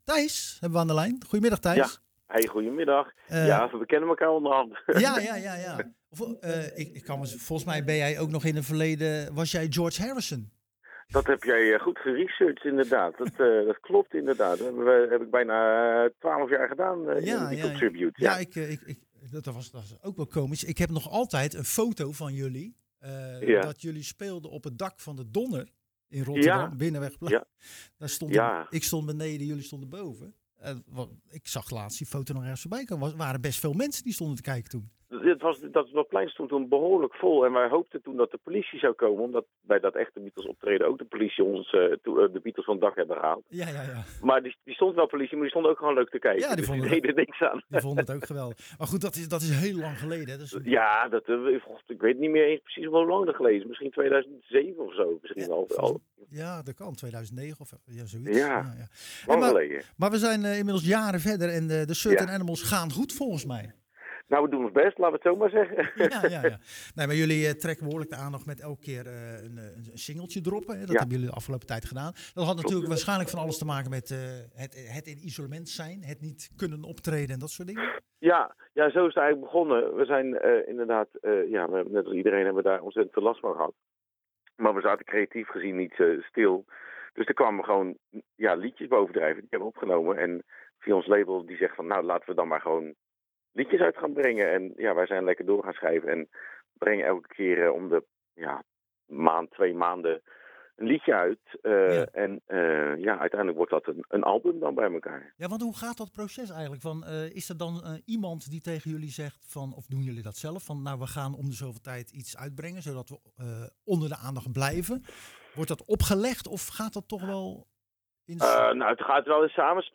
Tijdens het programma Zwaardvis belde we met zanger/gitarist